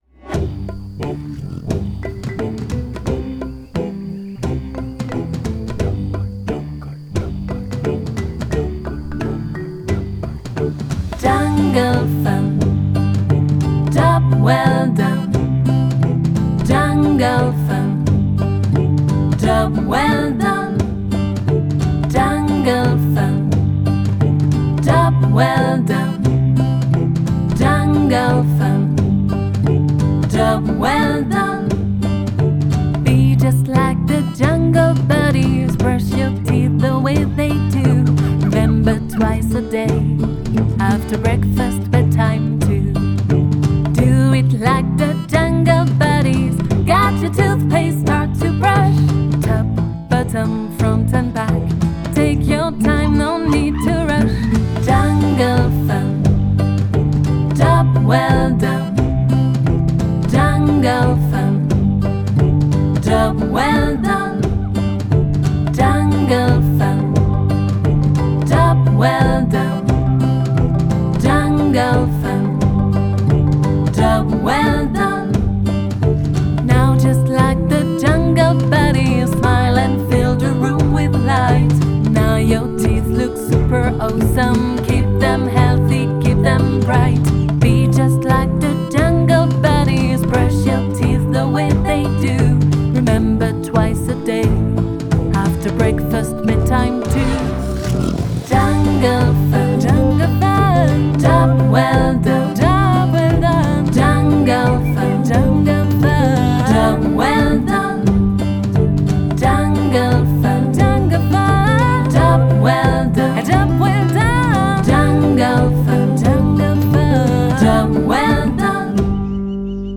Scarica la Storia Spazzola i denti e divertiti... cantando!